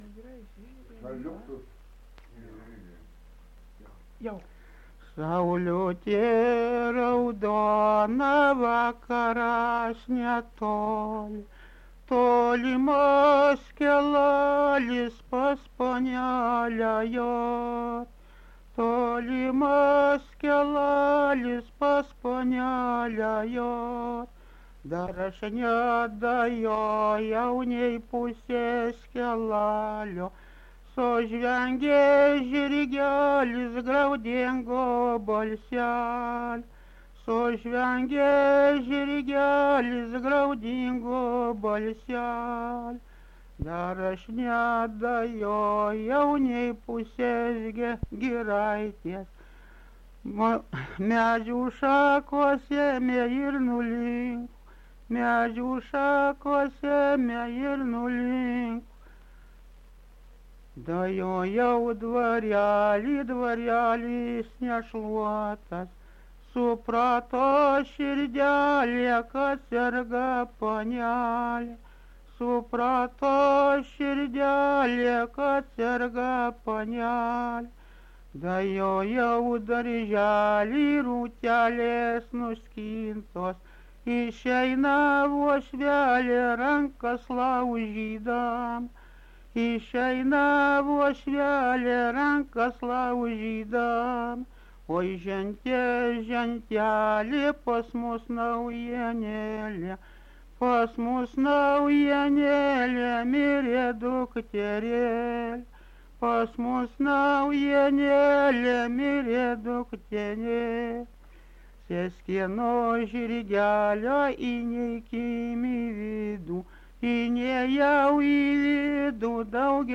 Dalykas, tema daina
Atlikimo pubūdis vokalinis
Nesigirdi pirmų žodžių